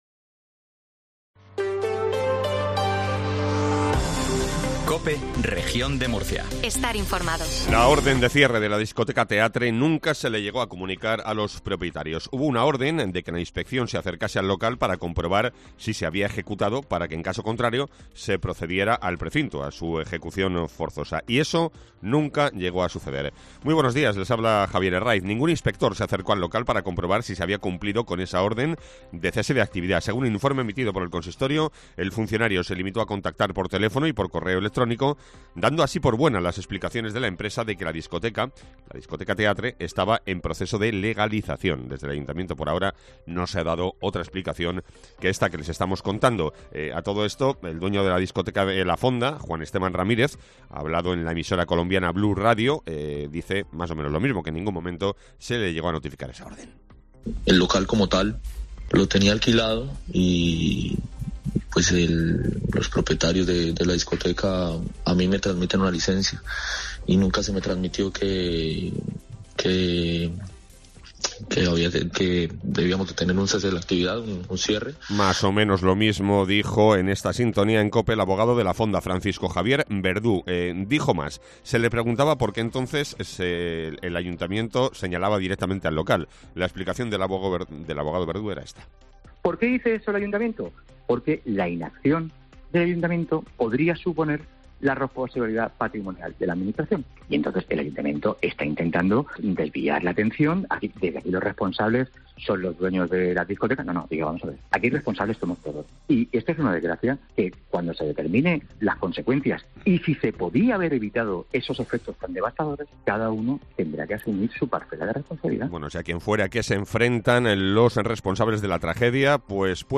INFORMATIVO MATINAL REGION DE MURCIA 0720